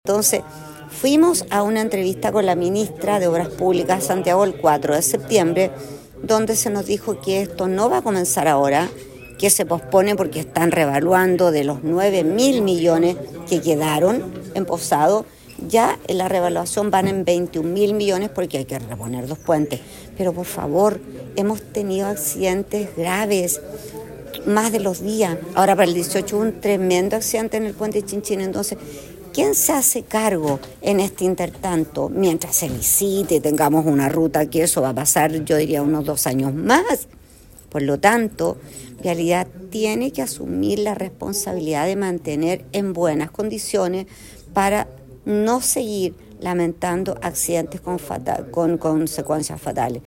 La Alcaldesa María Jimena Núñez, indicó que la Dirección Regional de Vialidad, debe asumir la responsabilidad de mantener el estado de la ruta, pues dadas sus condiciones se han suscitado múltiples accidentes de tránsito.